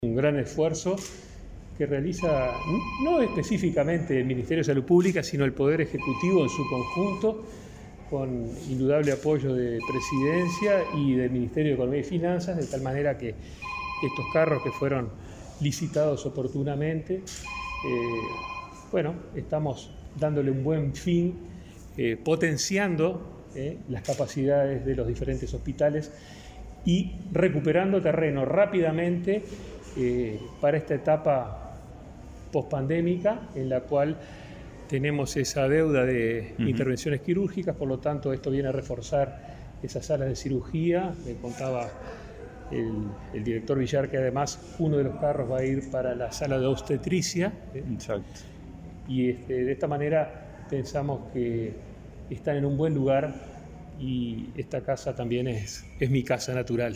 Declaraciones de prensa del ministro de Salud Pública, Daniel Salinas